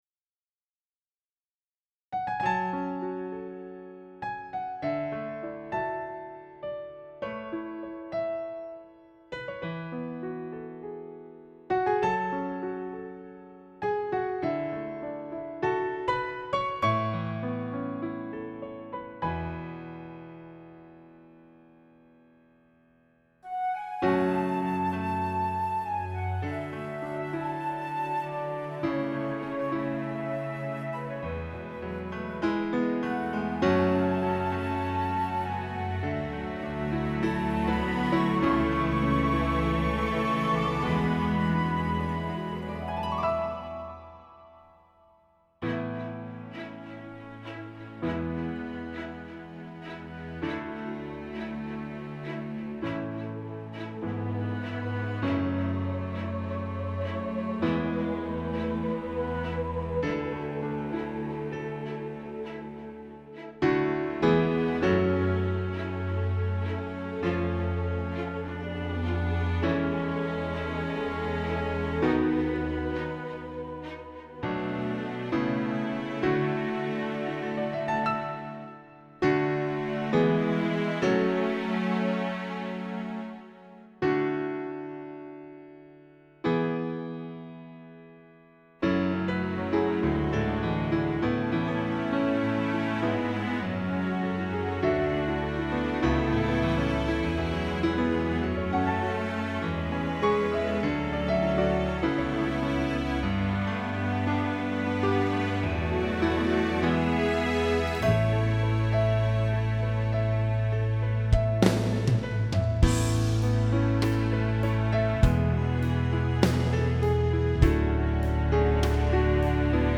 guitarless track